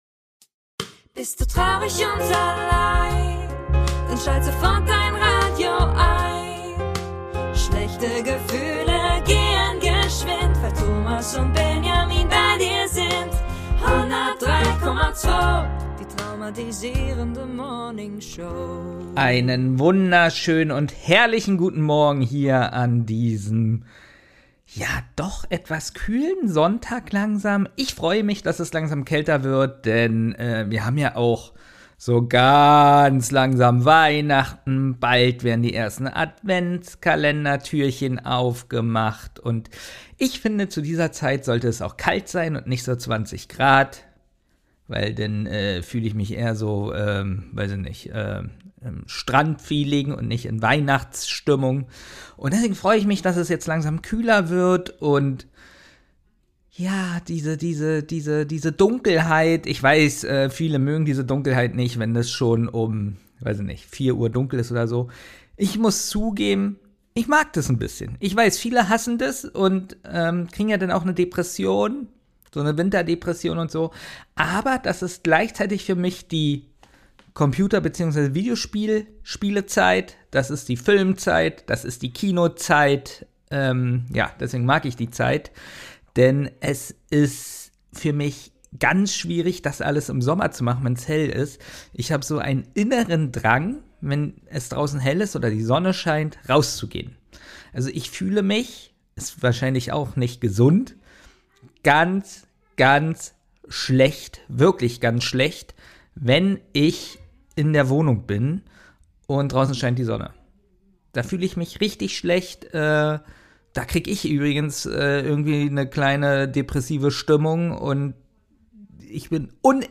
Aussenreport